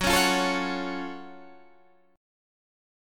F#mM7 chord